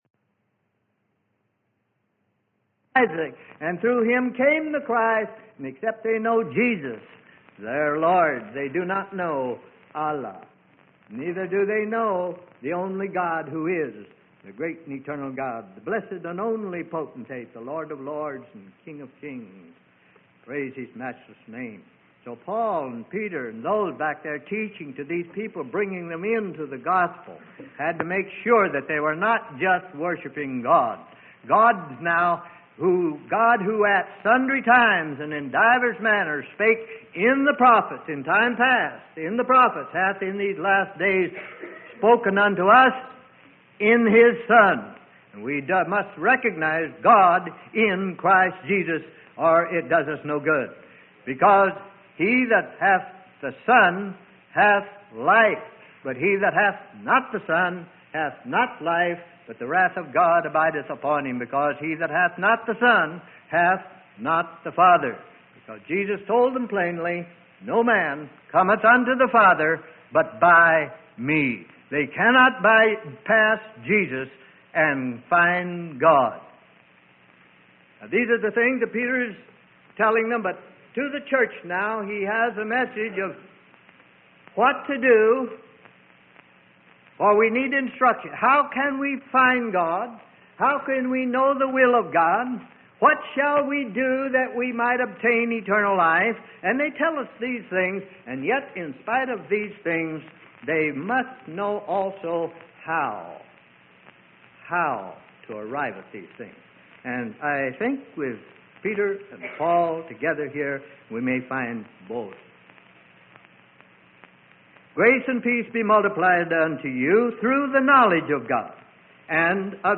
Sermon: Walking With God - Freely Given Online Library